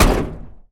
carKick2.ogg